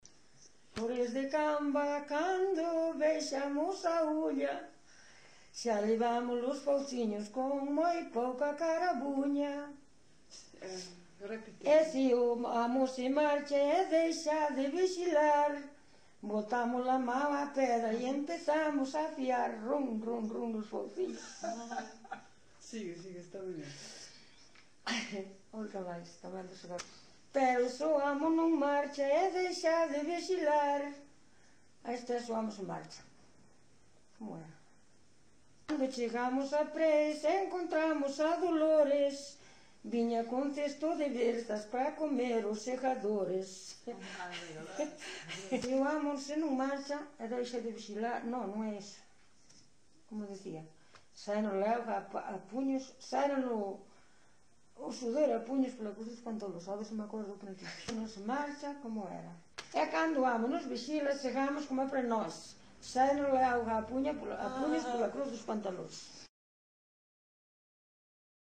Concello: Vila de Cruces.
Tipo de rexistro: Musical
Soporte orixinal: Casete
Datos musicais Refrán
Instrumentación: Voz
Instrumentos: Voz feminina